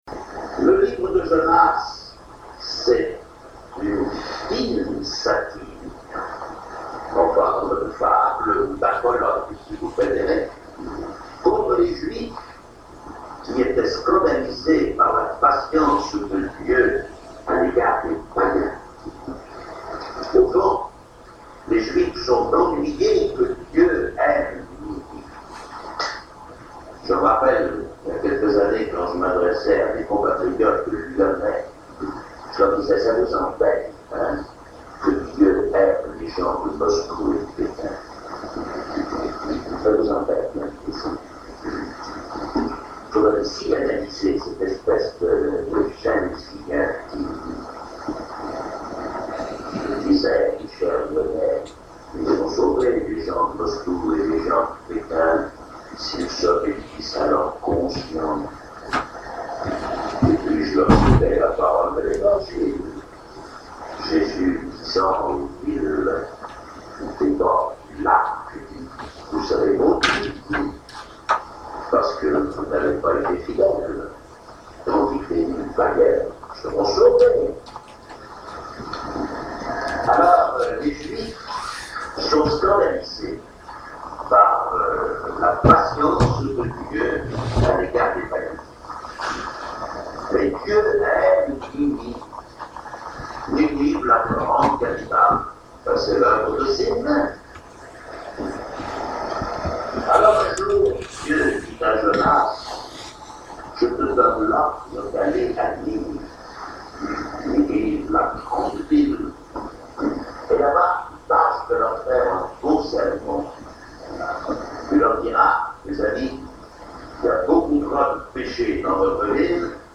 Le Père François Varillon raconte l’histoire de Jonas